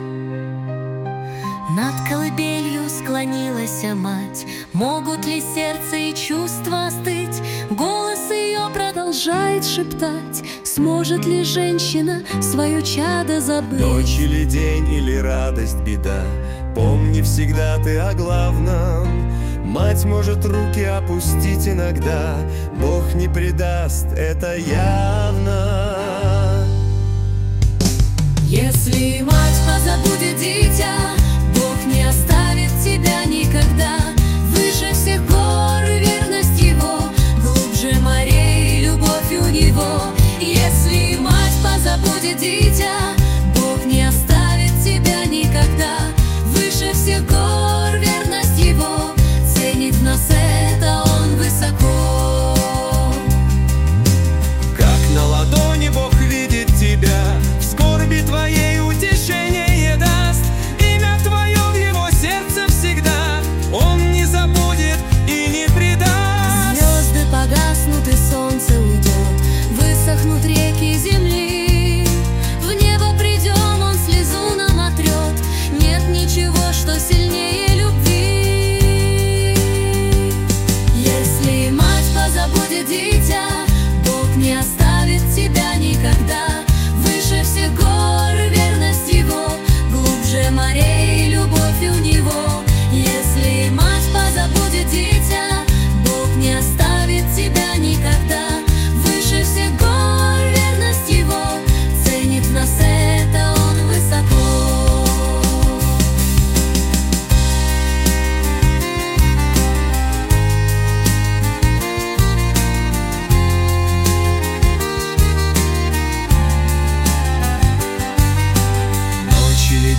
песня ai
351 просмотр 1122 прослушивания 102 скачивания BPM: 79